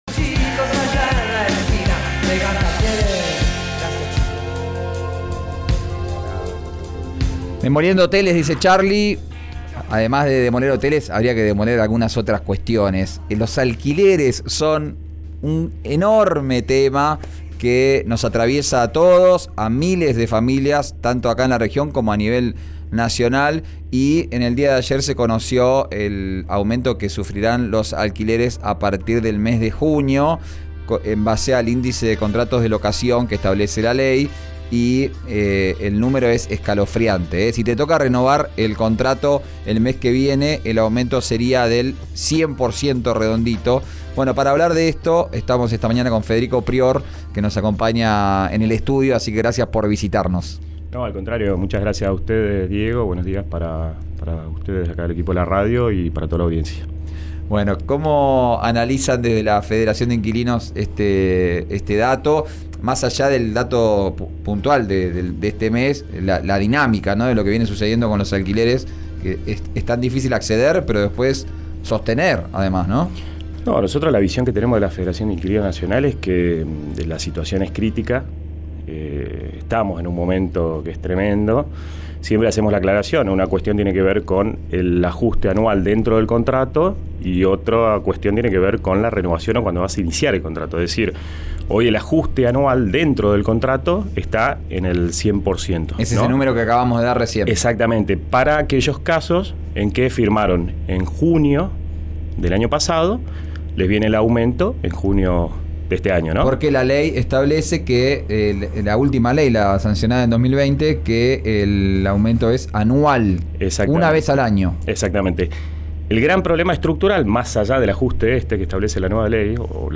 Los contratos de los alquileres firmados en junio del año pasado, se actualizarán el 100% en 2023. Desde RÍO NEGRO RADIO dialogamos con un representante de la Federación de Inquilinos y contestó consultas sobre cobros de renovación y depósito.